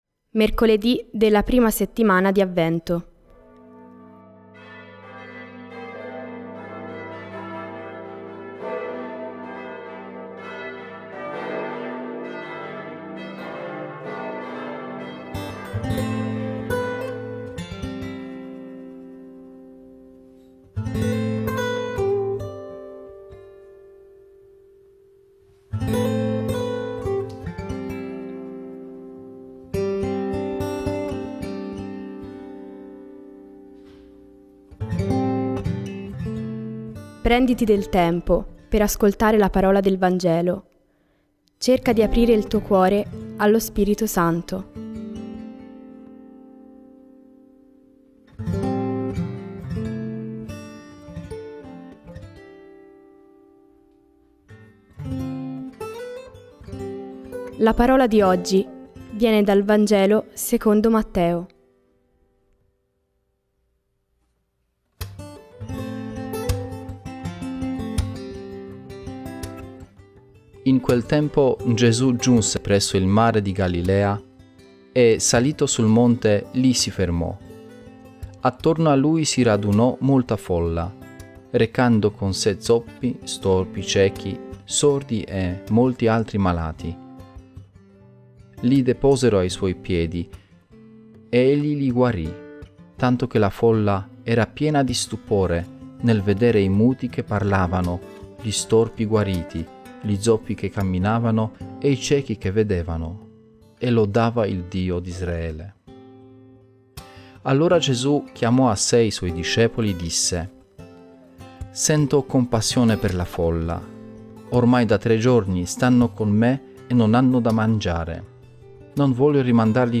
Voci narranti